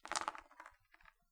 throw.wav